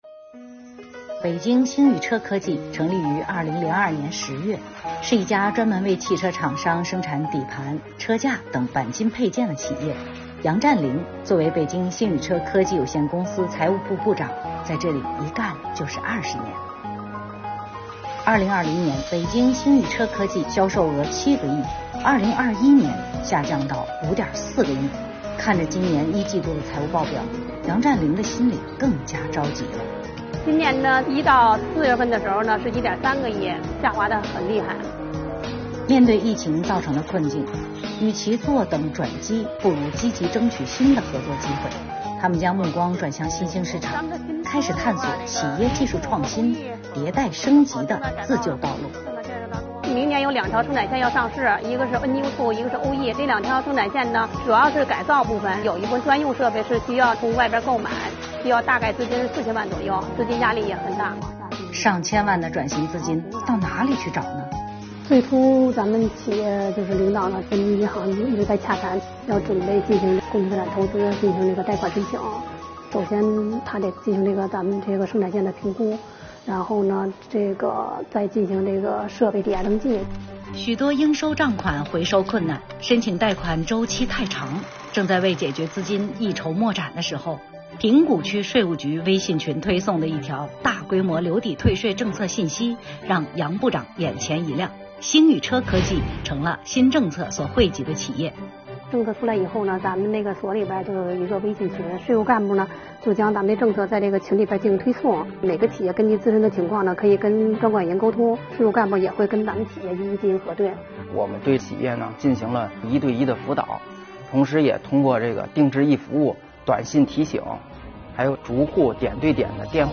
视频虽短但内容丰富，有财务人员讲述、车间展示、税务人员细致服务等场景，具有较高的新闻价值，音乐与内容也相得益彰。